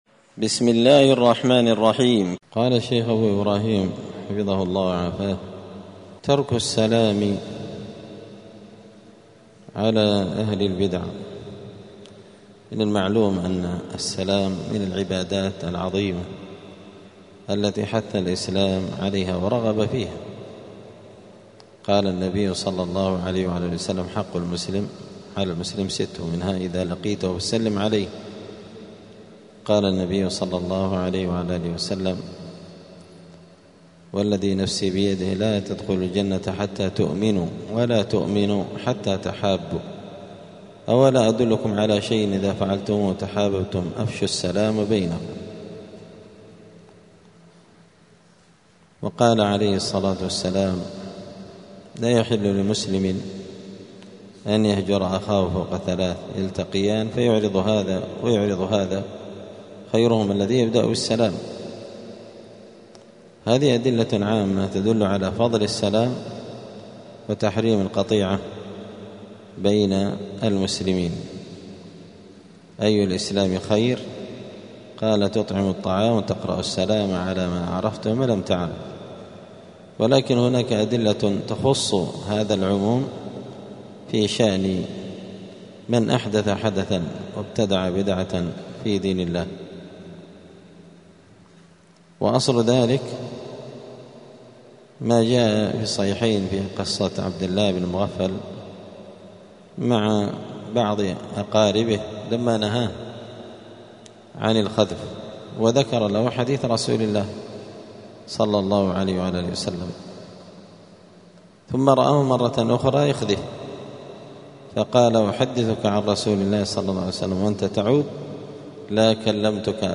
دار الحديث السلفية بمسجد الفرقان بقشن المهرة اليمن
*الدرس الواحد والثمانون (81) {ترك السلام على أهل البدع}*